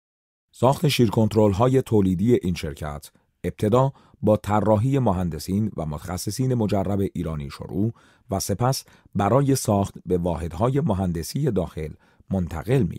• 6Persian Male No.4
Commercial